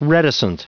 Prononciation du mot reticent en anglais (fichier audio)
Prononciation du mot : reticent